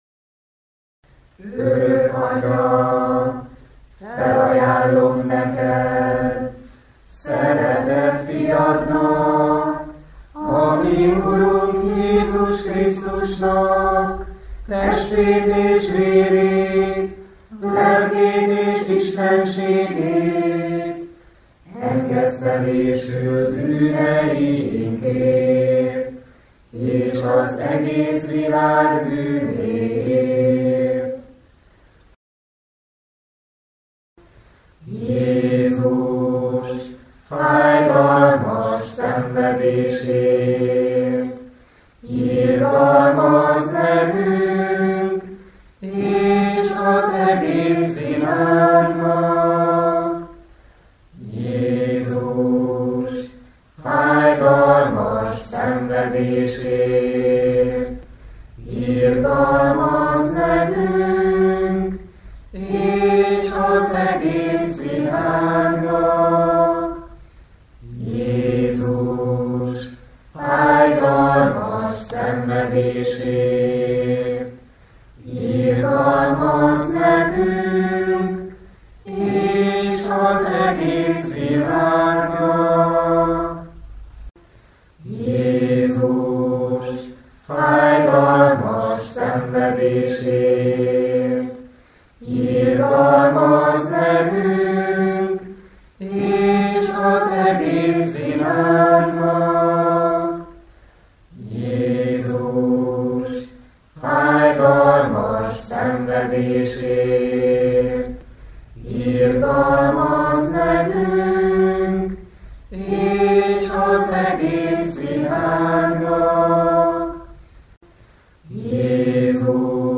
Énekelve az irgalmasság rózsafüzére